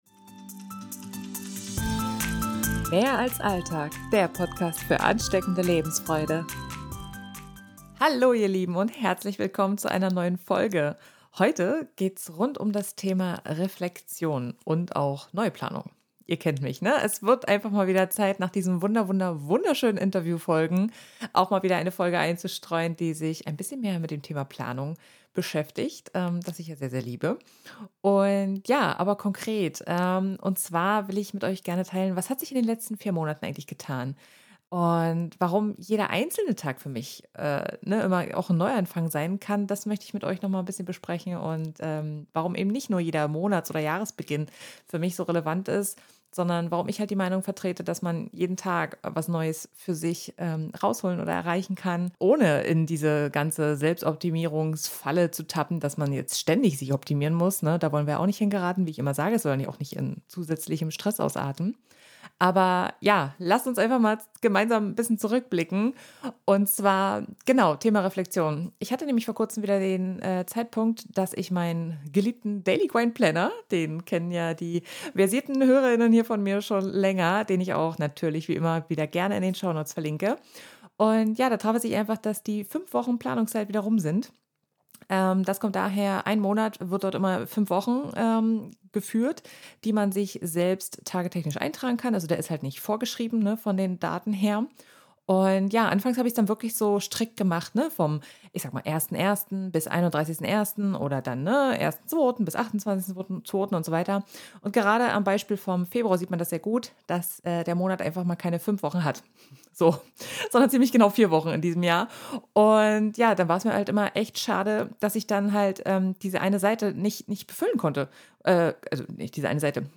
Intro-/Outromusik des Podcasts